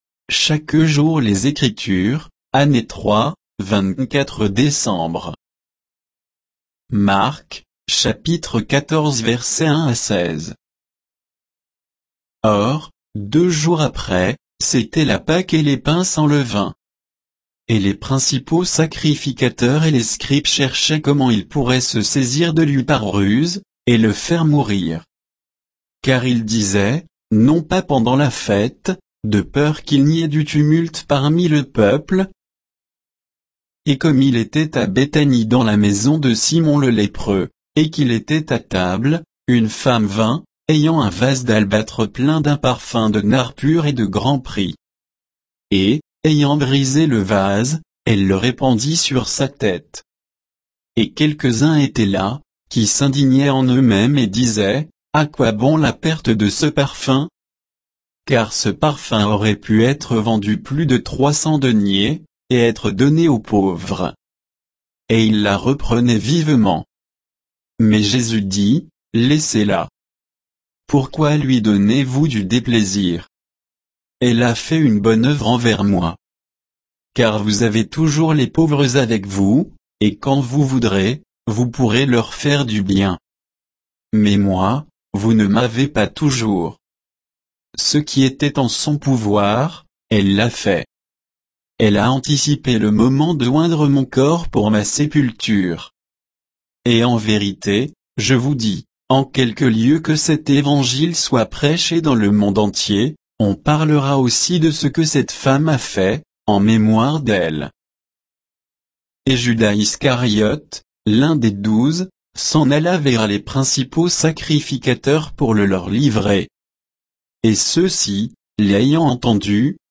Méditation quoditienne de Chaque jour les Écritures sur Marc 14, 1 à 16